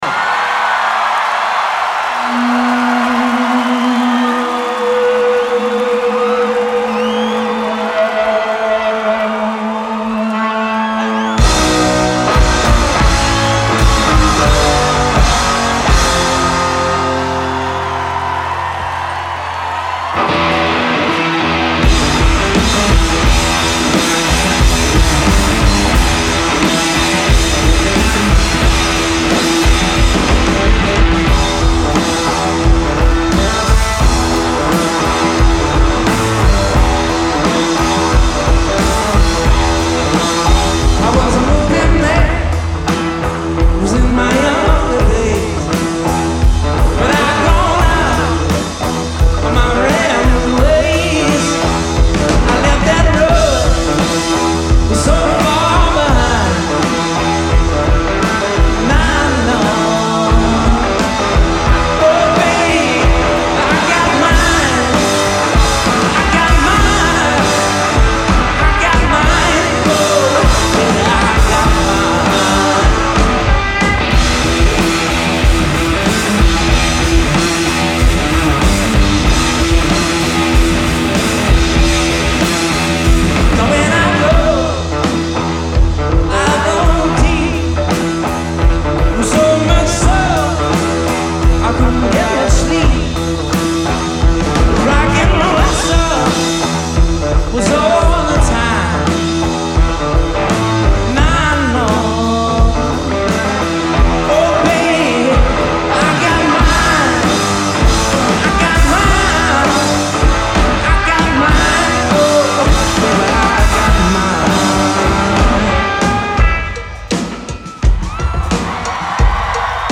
Genre : Alternative, Indie
Live in Portland, ME